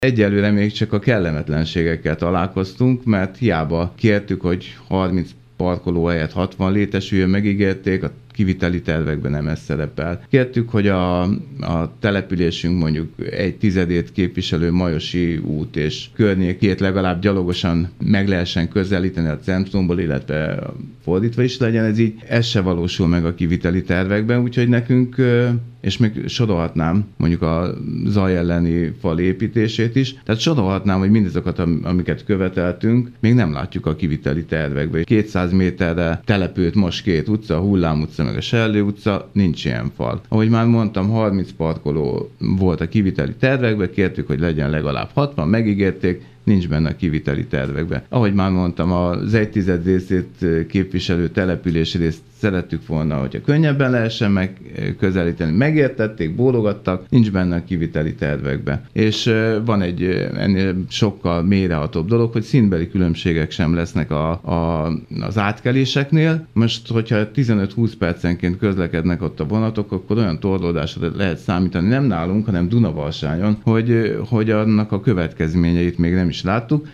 Dr. Riebl Antal polgármester sorolta a meg nem valósult kéréseket.